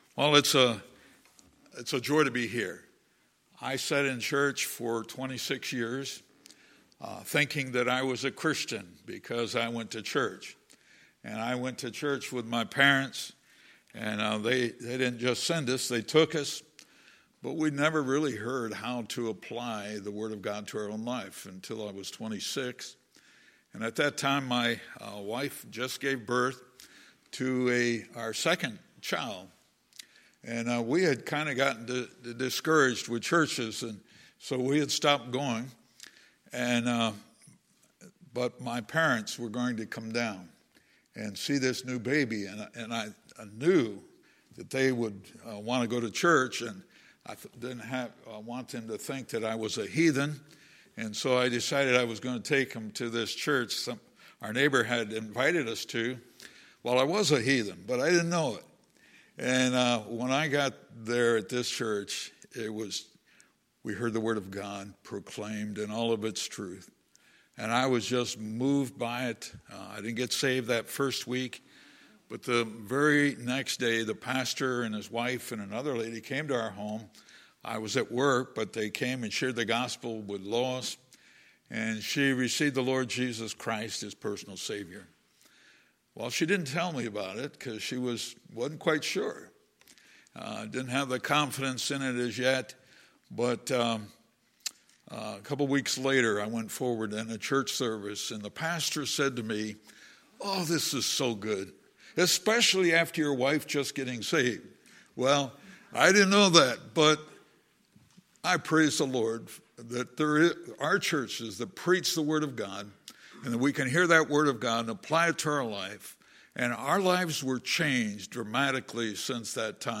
Sunday, November 10, 2019 – Sunday Morning Service